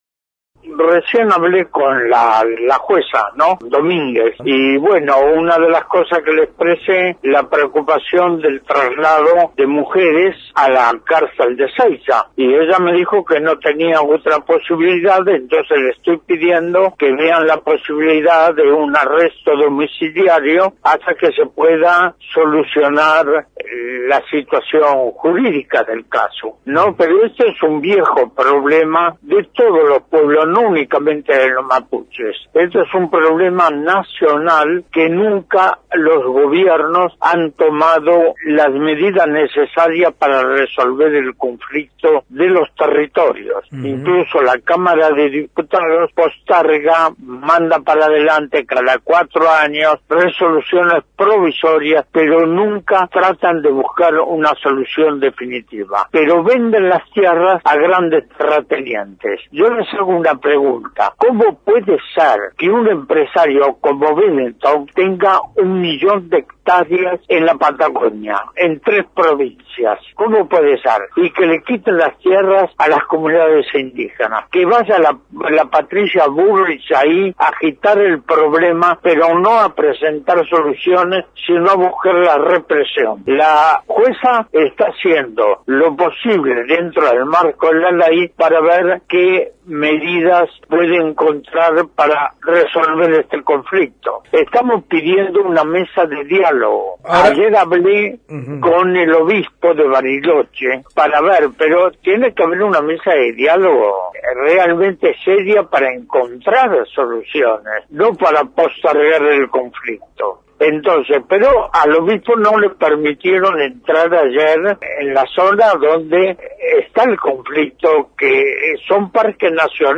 El Premio Nóbel de la Paz, dialogó con Radio Nacional Esquel sobre la situación de las mujeres que fueron detenidas en el marco del operativo en Villa Mascardi. Pérez Esquivel confirmó que habló con el presidente de la nación y con la jueza que ordenó el desalojo de la Lof Lafquen Winkul Mapu, a quienes les reclamó por una mesa de diálogo y una salida pacífica a este conflicto. Escuchá su palabra en diálogo con el programa Trabajo de Hormiga: